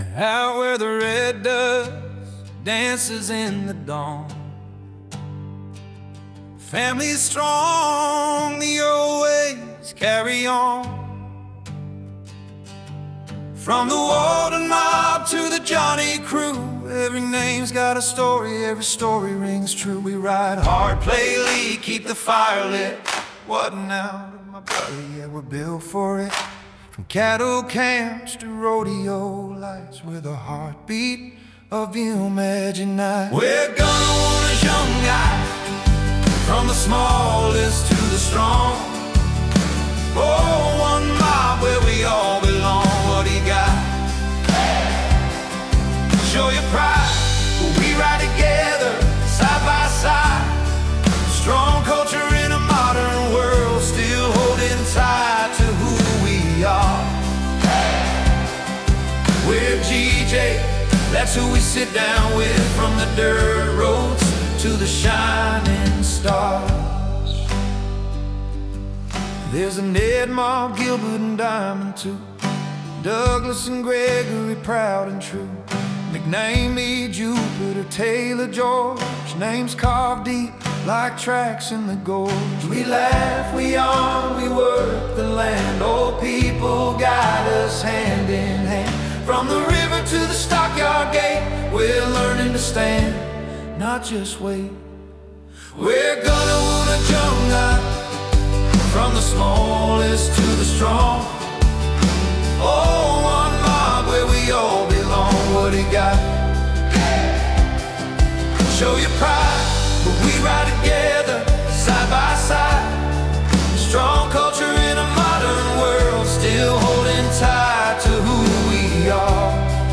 gunawuna-jungai-country-anthem.mp3